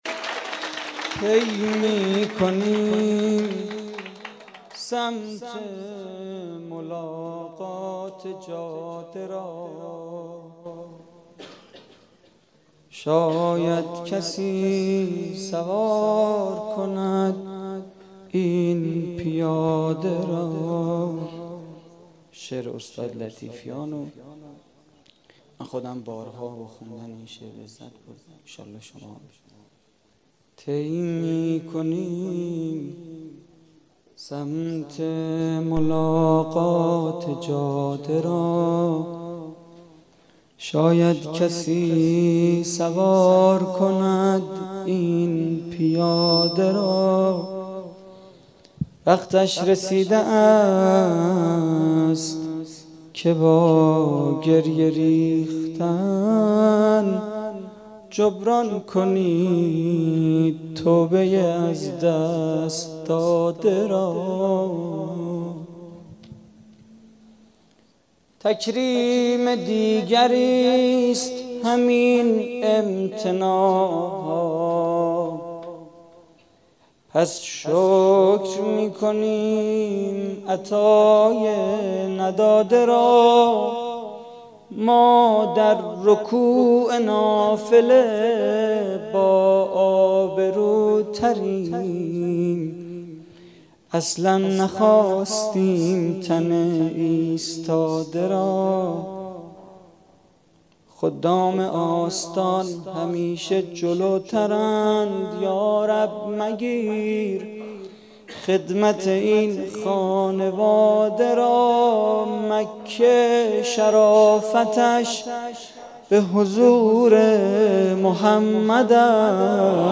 مدیحه: جبران کنید توبه‌ی از دست داده را
جشن عید مبعث